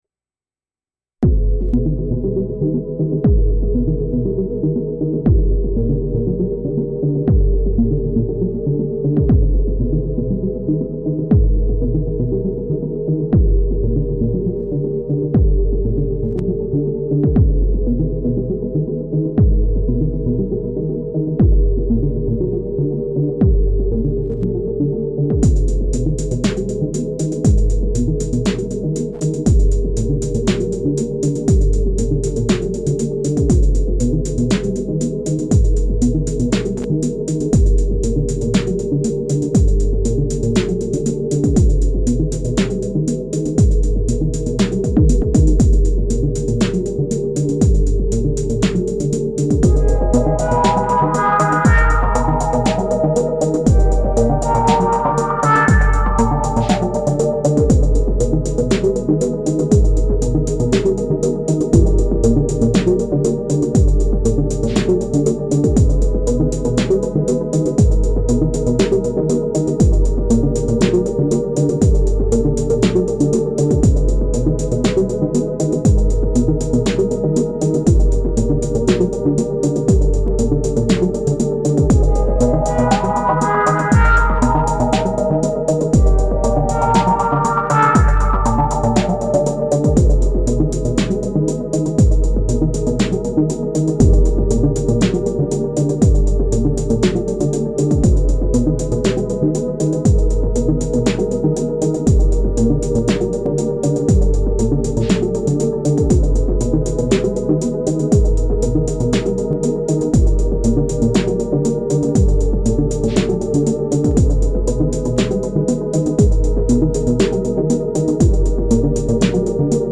Three sketches.
Straight from the Syntakt. Something’s wrong with my audio interface, apologies for the occasional boiling in the right channel.